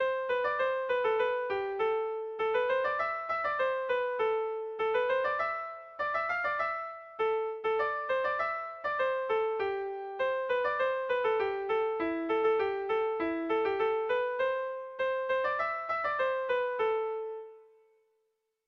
ABDEB